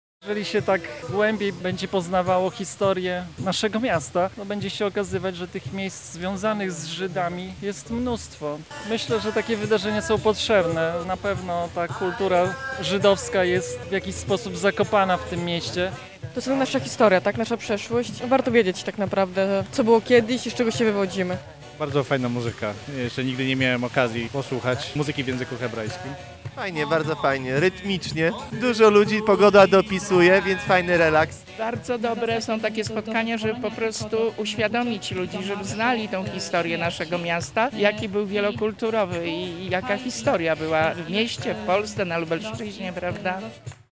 Sympatycy izraelskiej kultury mieli okazję usłyszeć tradycyjną muzykę na żywo.